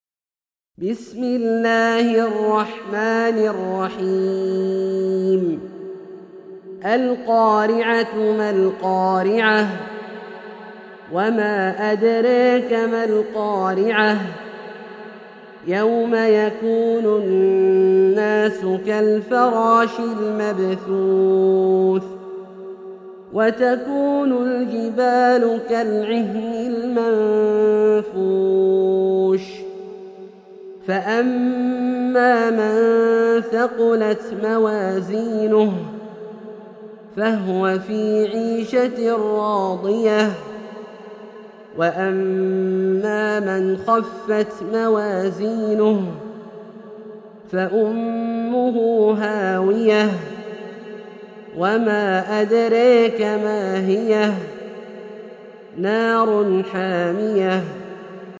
سورة القارعة - برواية الدوري عن أبي عمرو البصري > مصحف برواية الدوري عن أبي عمرو البصري > المصحف - تلاوات عبدالله الجهني